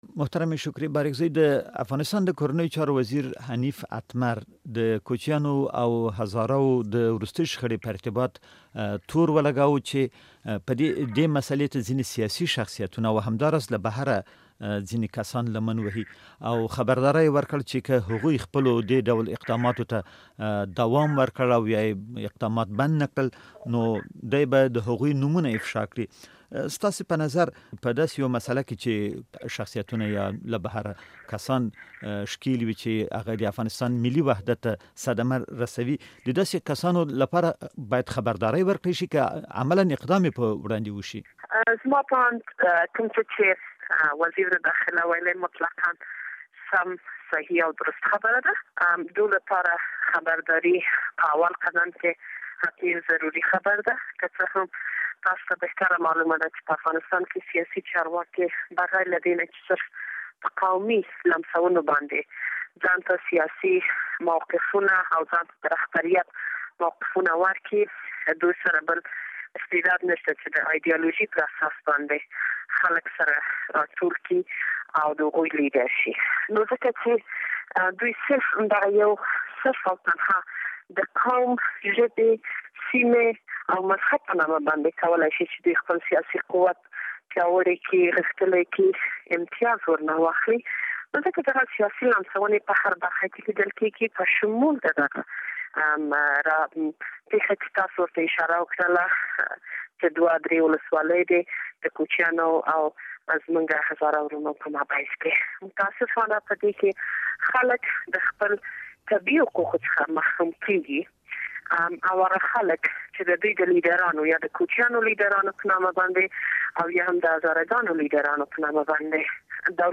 له شکریې بارکزی سره مرکه واورﺉ